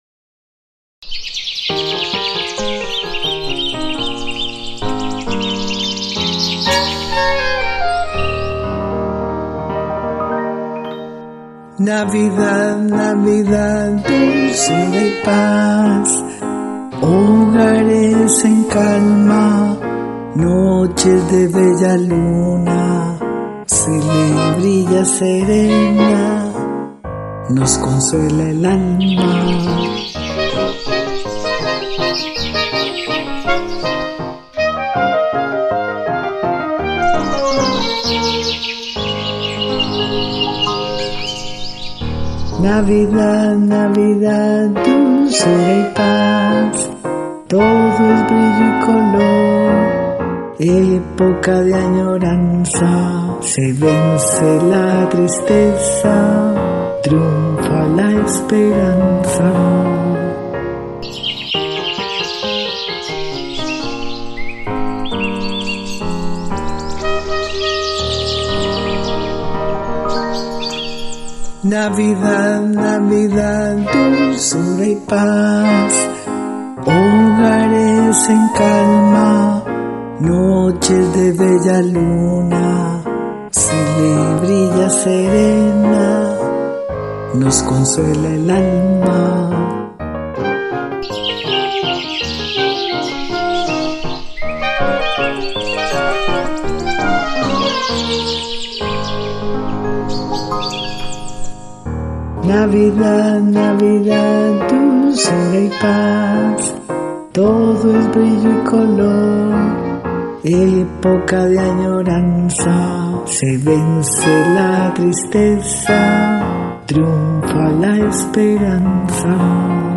piano y saxofones